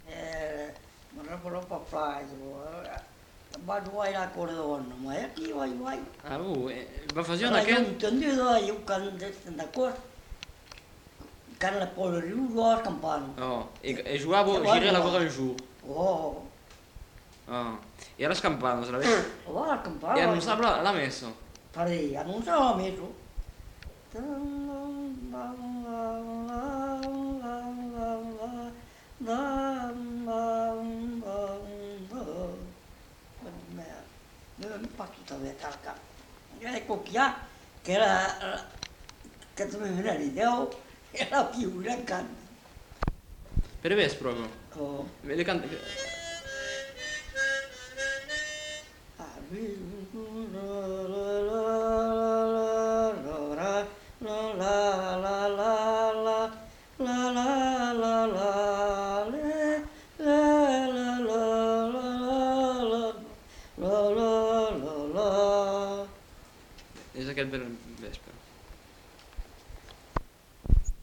Aire culturelle : Cabardès
Genre : chant
Effectif : 1
Type de voix : voix d'homme
Production du son : fredonné